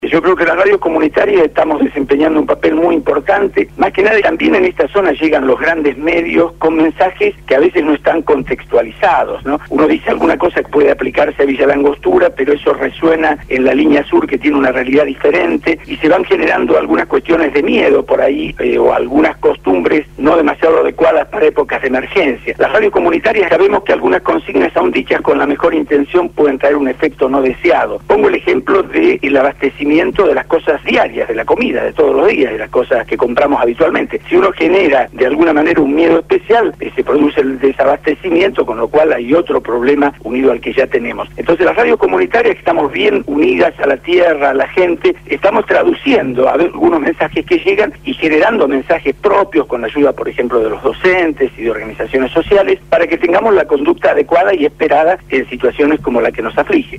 INFORME DESDE LA PATAGONIA: LA ERUPCIÓN DEL VOLCÁN PUYEHUE EN CHILE